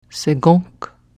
Segonk pronunciation only